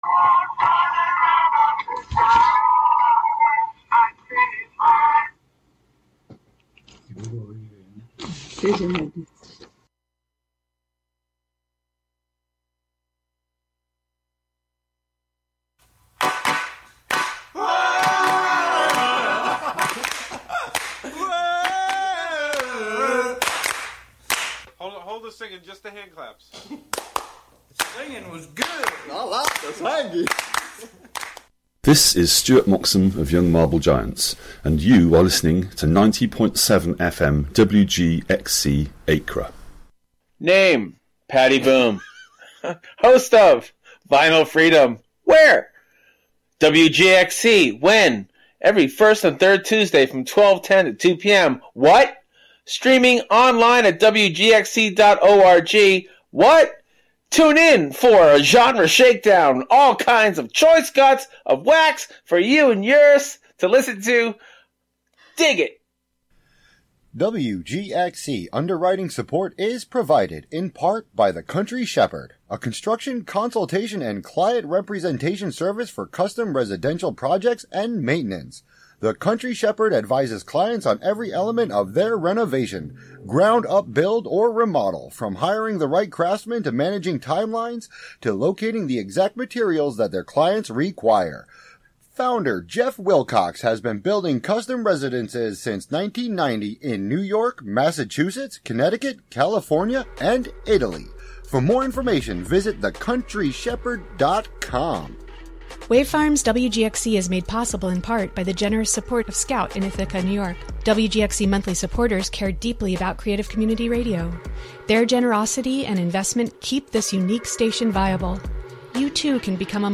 7pm Monthly program featuring music and interviews fro...
broadcast live from WGXC's Hudson studio.